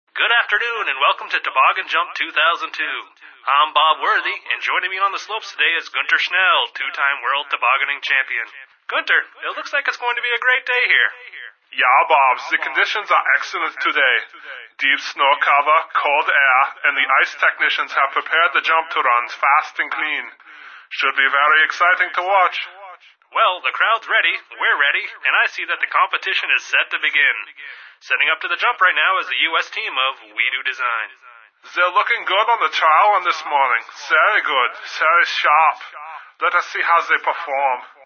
1 channel
2_introvoices.mp3